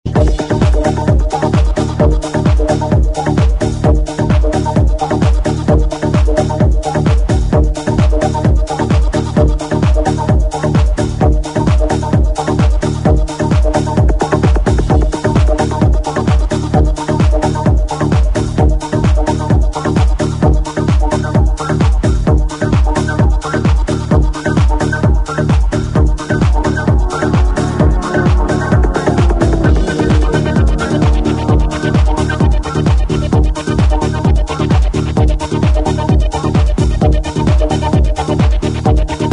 Genre: Electronic
Style: Progressive Trance